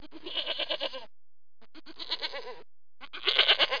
دانلود صدای بز برای کودکان از ساعد نیوز با لینک مستقیم و کیفیت بالا
جلوه های صوتی